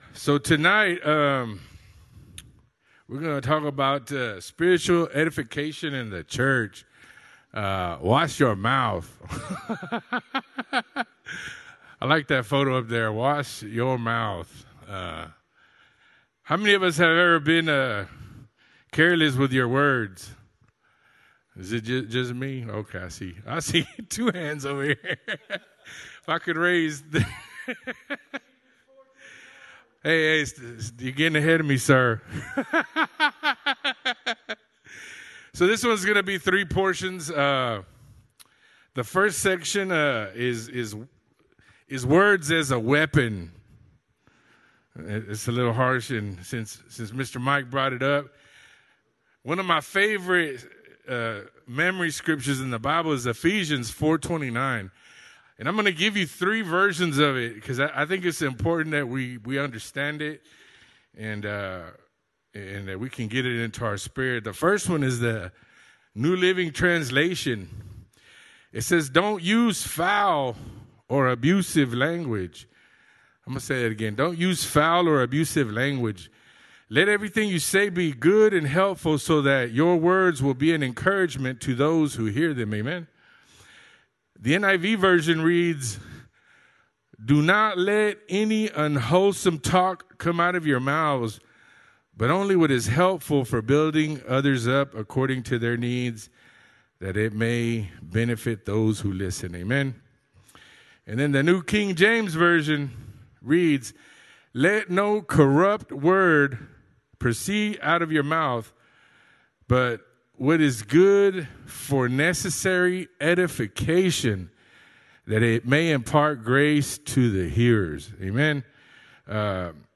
Sermons | Grace Pointe Church